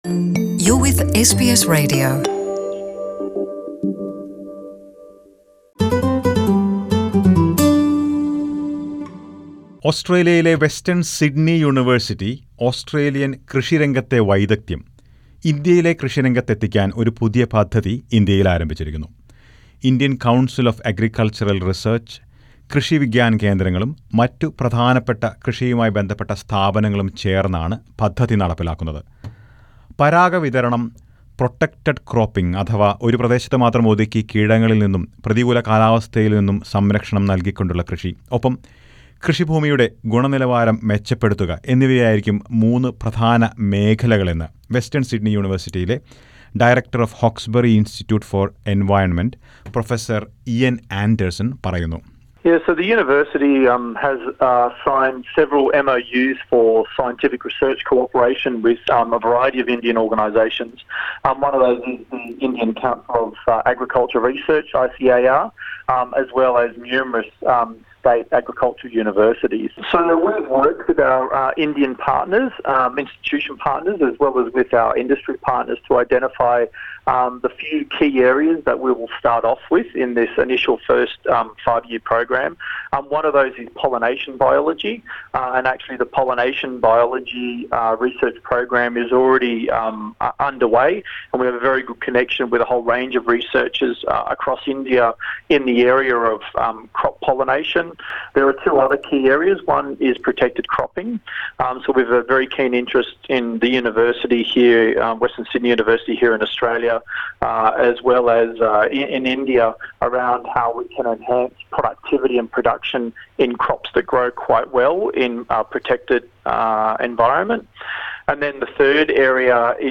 Western Sydney University has tied up with Indian Council of Agricultural Research (ICAR) and thirteen state agricultural universities to improve productivity of the farming sector in India. Listen to a report on this.